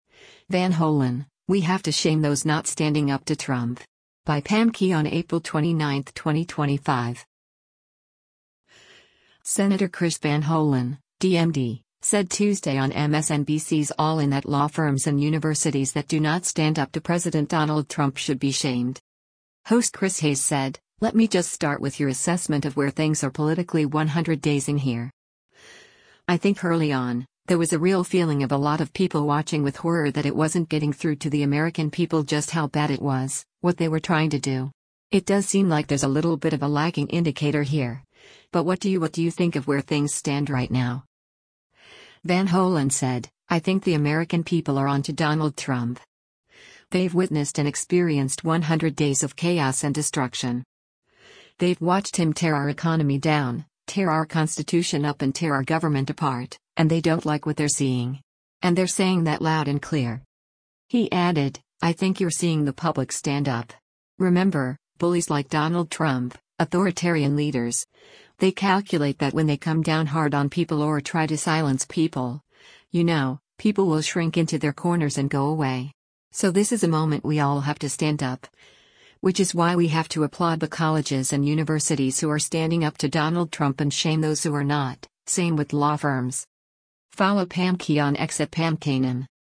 Senator Chris Van Hollen (D-MD) said Tuesday on MSNBC’s “All In” that law firms and universities that do not stand up to President Donald Trump should be shamed.